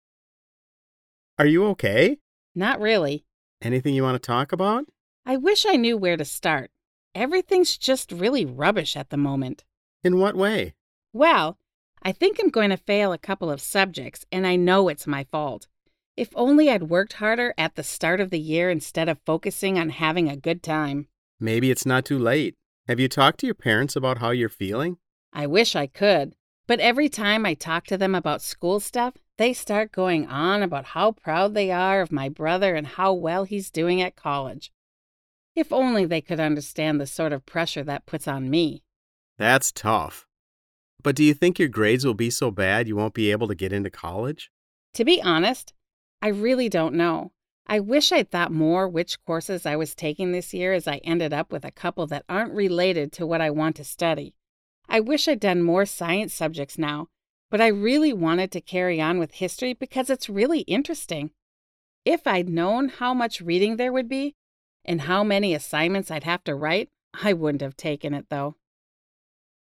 RH5 3.1_conversation.mp3